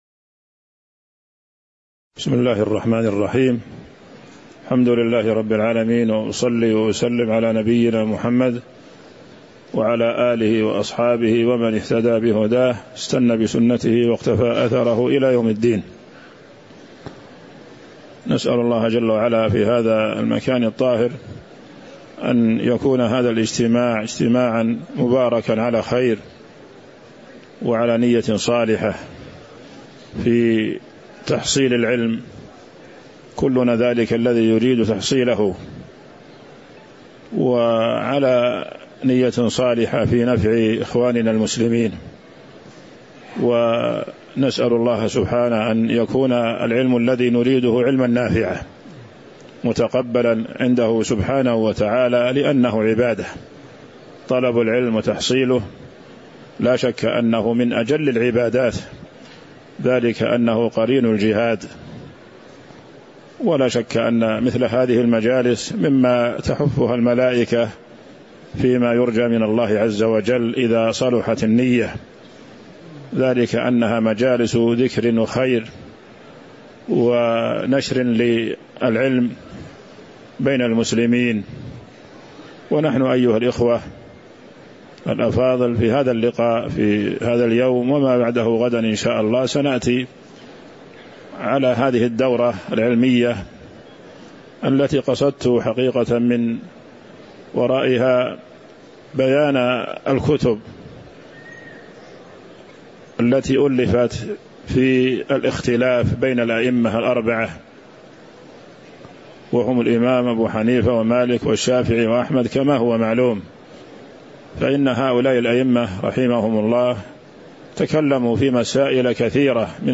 تاريخ النشر ١٠ ربيع الأول ١٤٤٦ هـ المكان: المسجد النبوي الشيخ